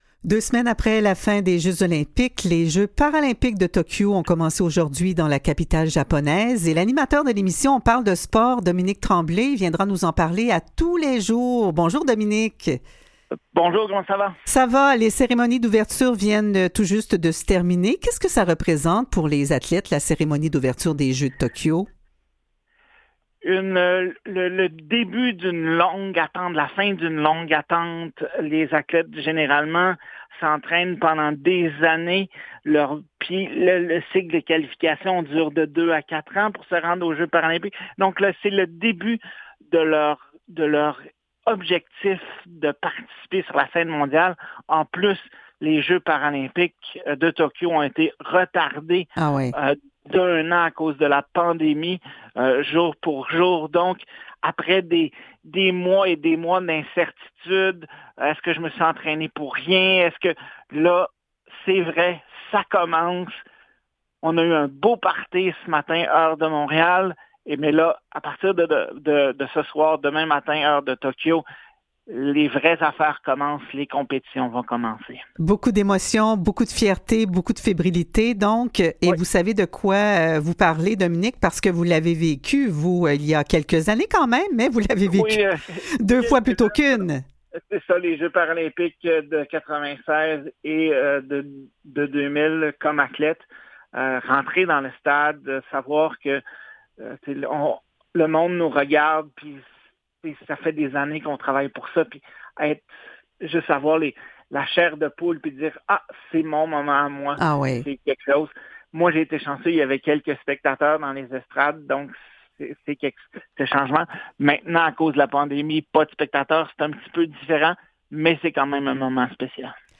Aux Quotidiens Revue de presse et entrevues du 24 août 2021